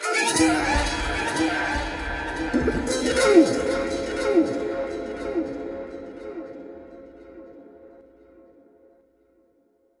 Guitar sounds » Guitar acstg v2201b2 62 9
描述：Another short gentle guitar like sound.
标签： electronic guitar music
声道立体声